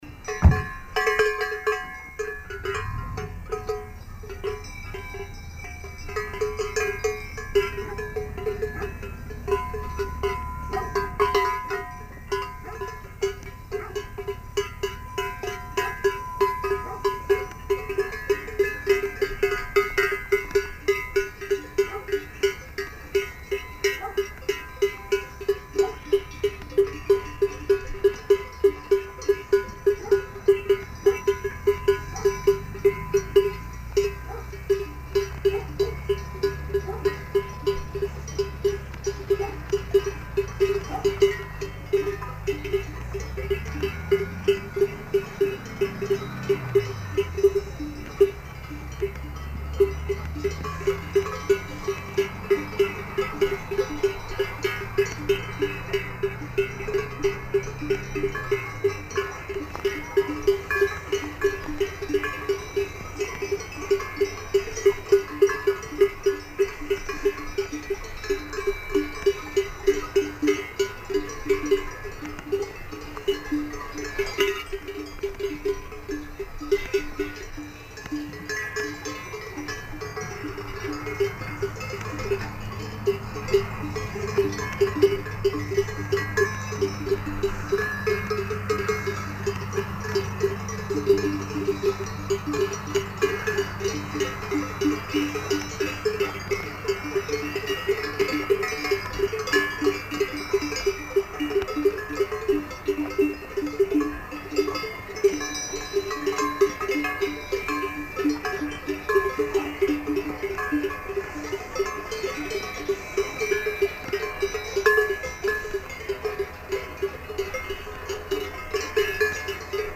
cow-bell-orchestra-swiss-alps-1973-mp3.mp3